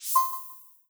Success12b.wav